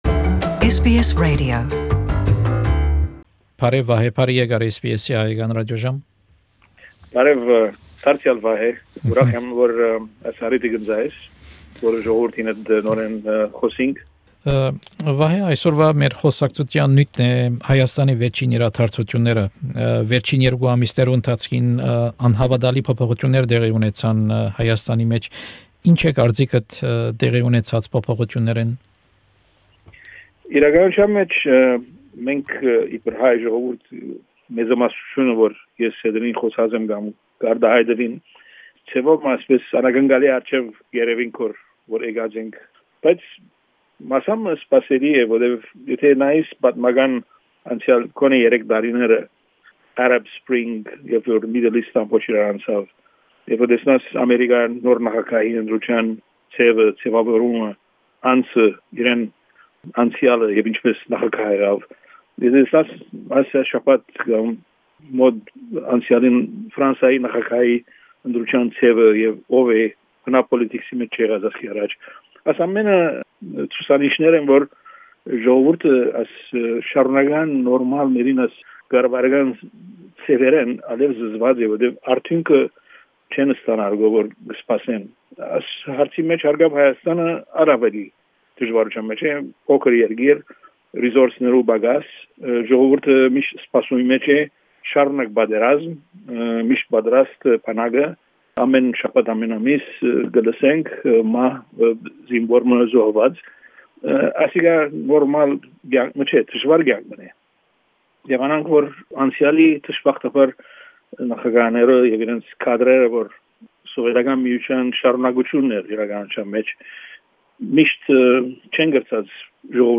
An interview (in Armenian)